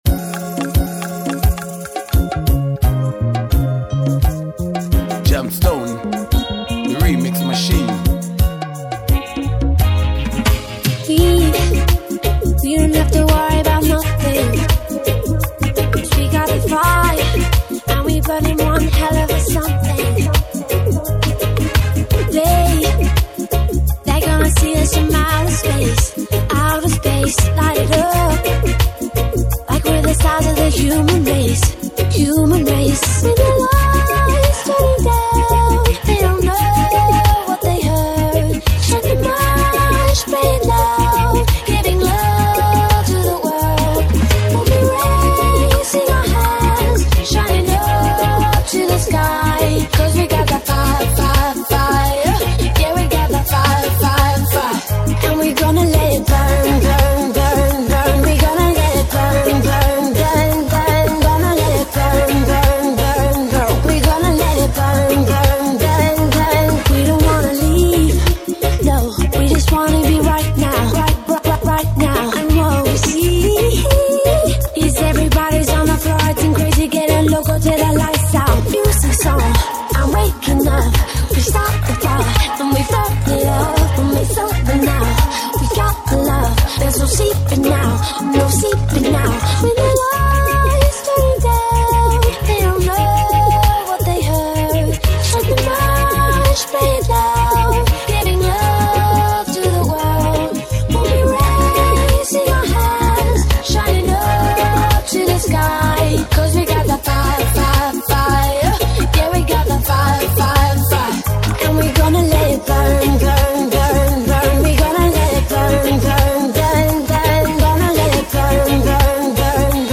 Soul Reggae riddim